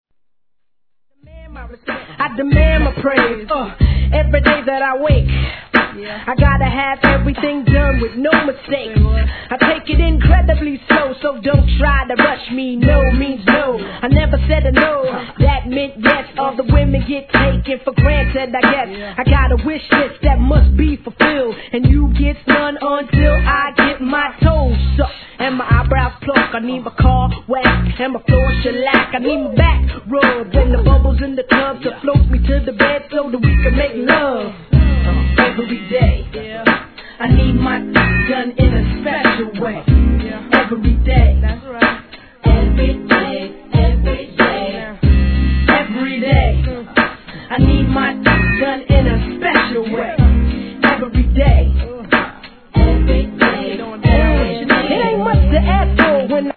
1. HIP HOP/R&B
1.RADIO VERSION CLEAN -